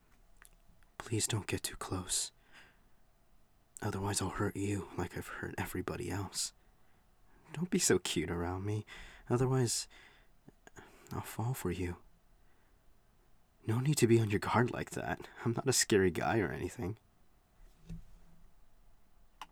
Voice: Medium to deep pitched, suave, maybe calm, possibly a bit mature.